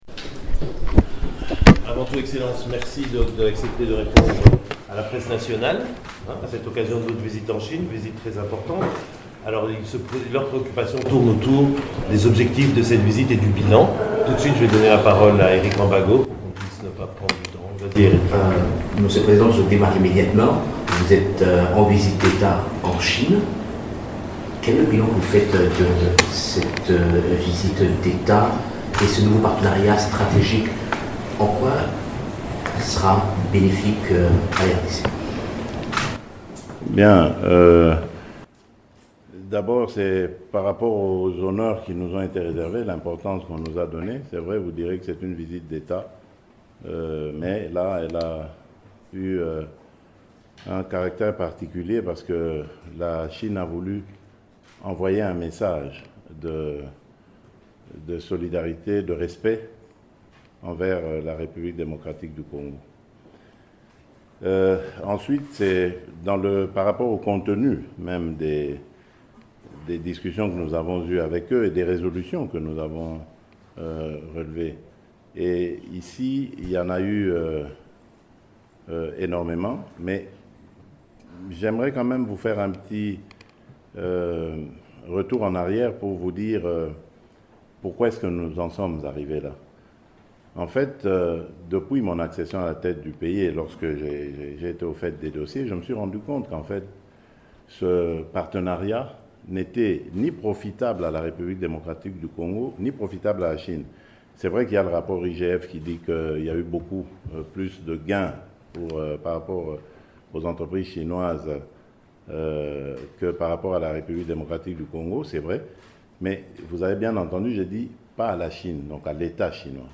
Le chef de l’Etat congolais l’a dit à la presse congolaise à Shenzhen (Chine), à la fin de sa visite de quatre jours en République populaire de Chine.
_conference_de_presse_f-a_tshisekedi_a_shenzhen-00.mp3